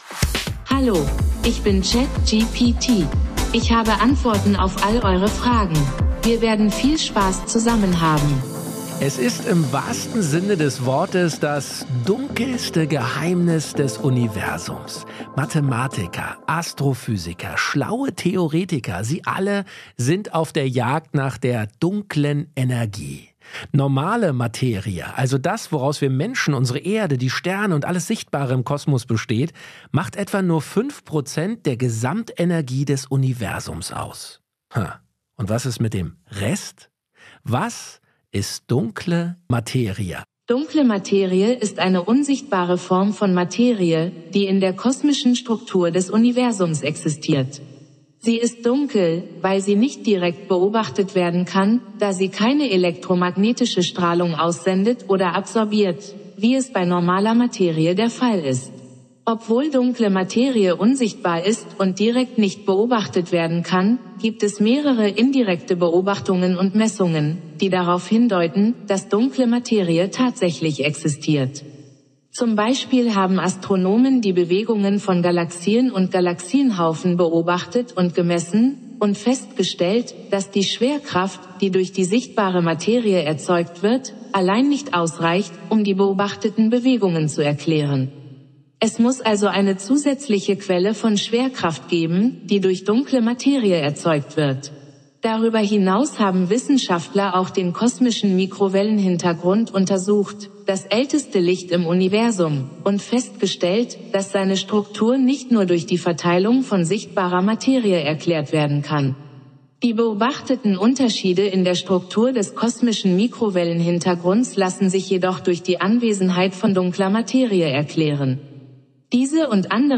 Podcast mit der Künstlichen Intelligenz ChatGPT von OpenAI als